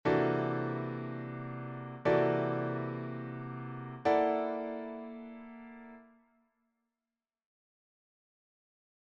La triade et le triton peuvent être renversés :